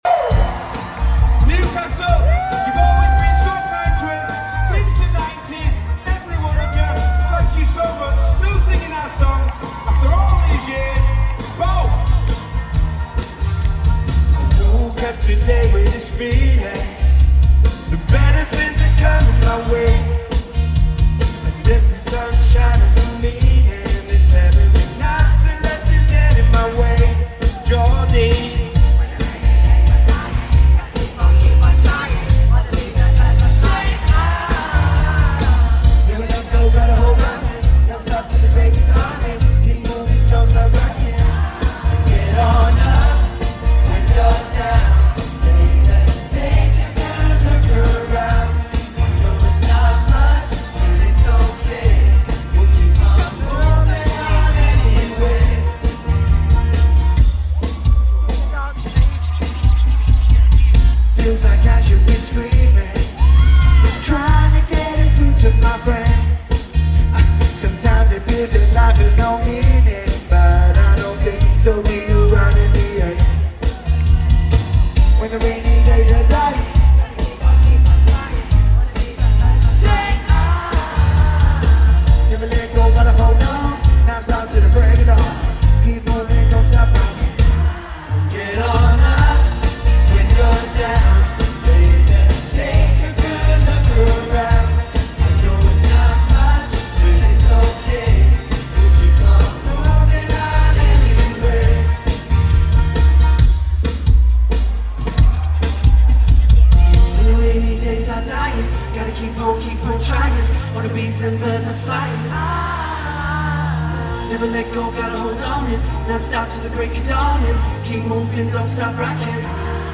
performing
#90sboyband